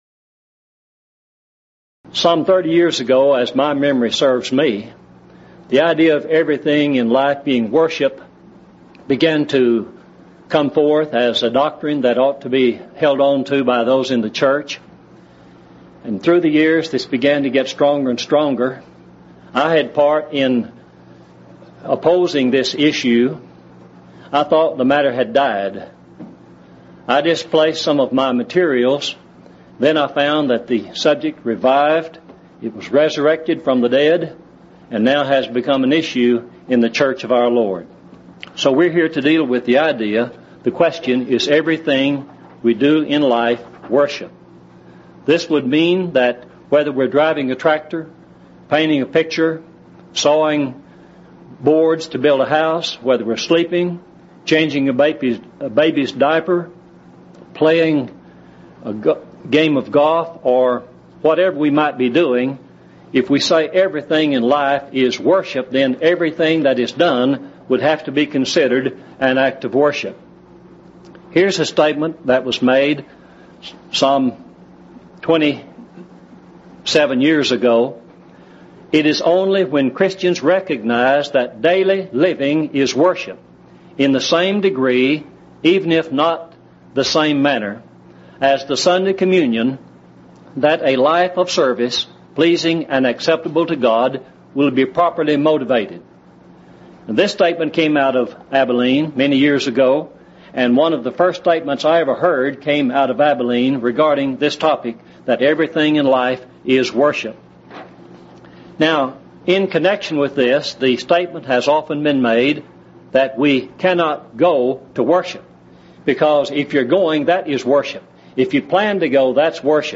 Event: 2nd Annual Lubbock Lectures
lecture